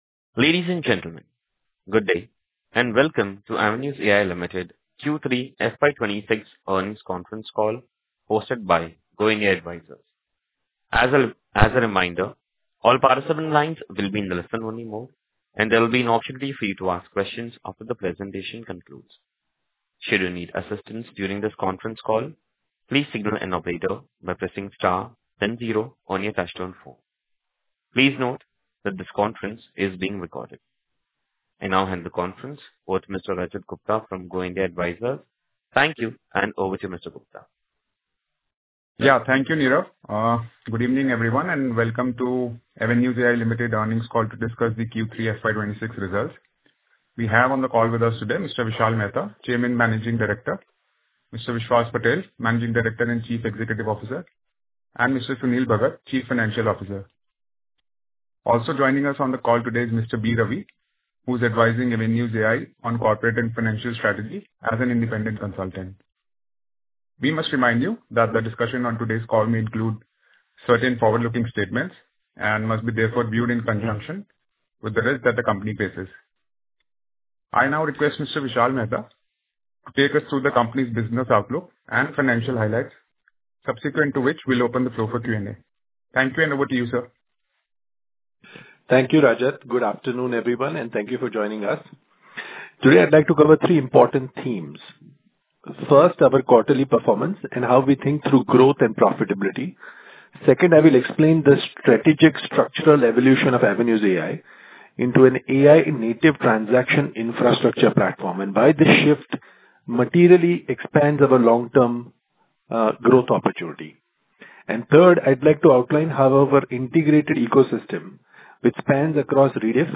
Concalls
Q3-FY26-Earnings-Call-Audio.mp3